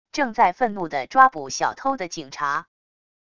正在愤怒的抓捕小偷的警察wav音频